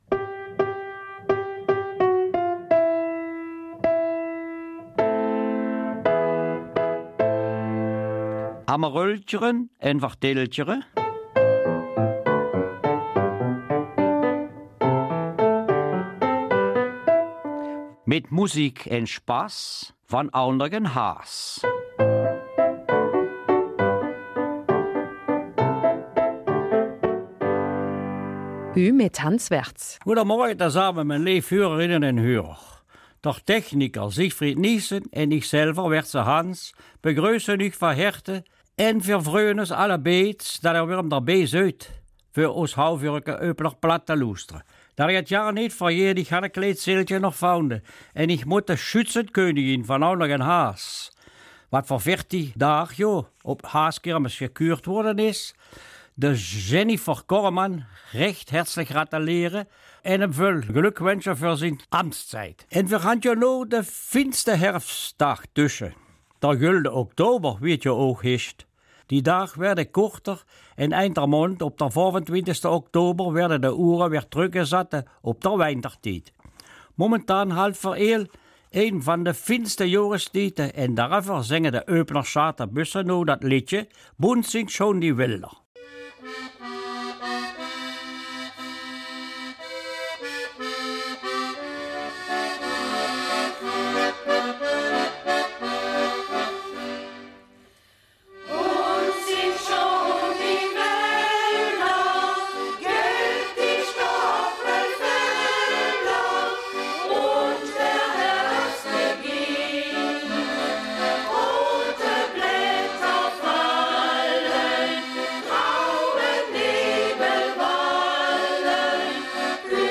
Eupener Mundart: Belebung des Bergviertels